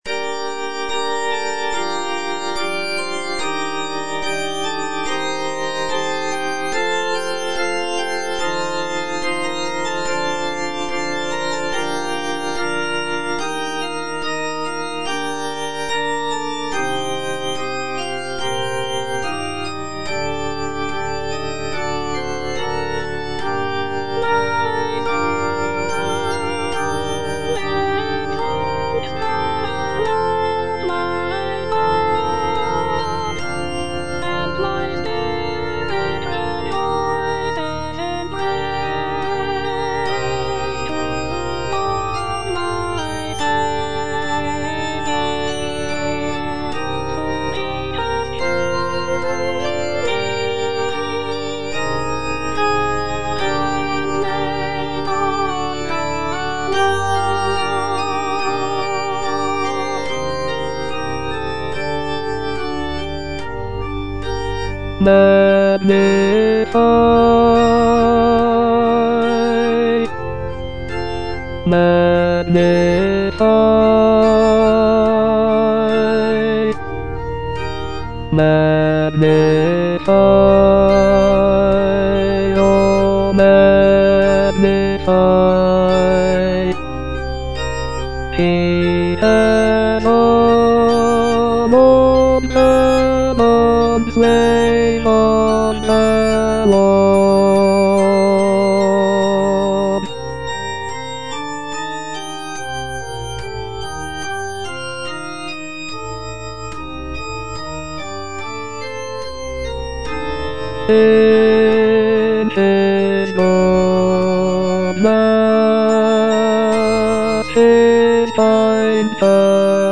Bass (Voice with metronome)